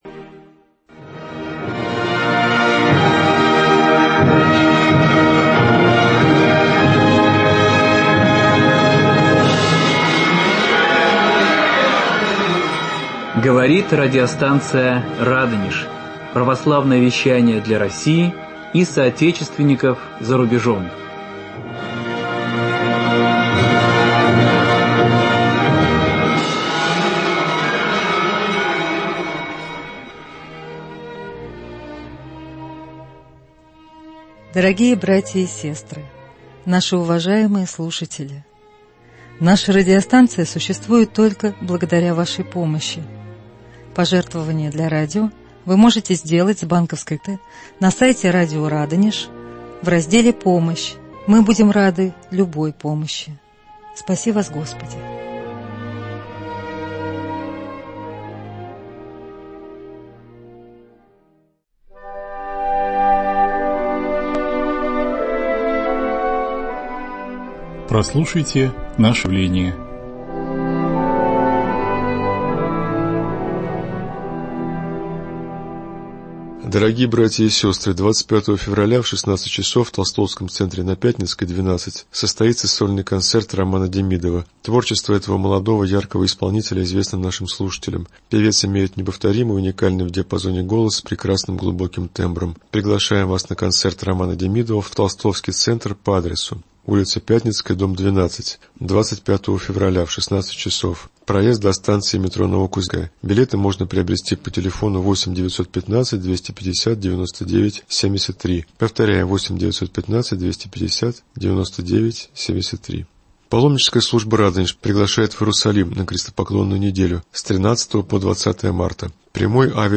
Прямой эфир.